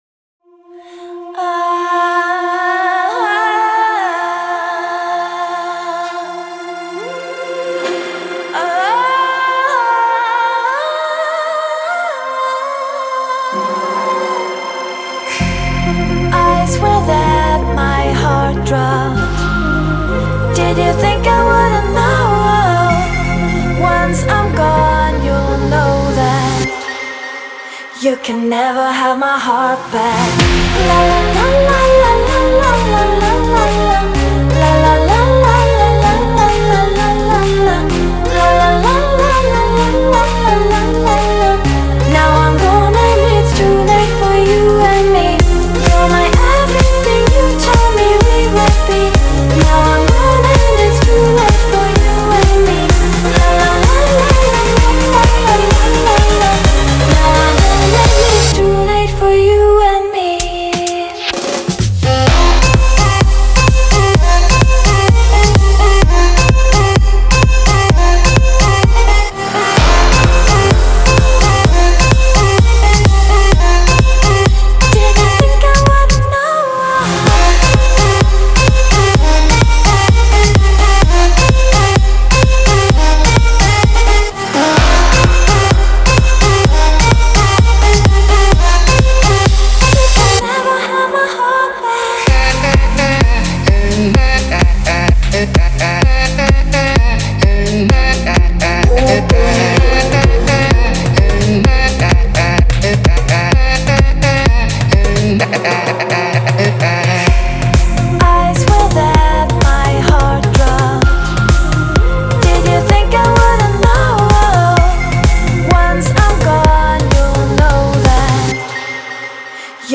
это яркая и энергичная композиция в жанре EDM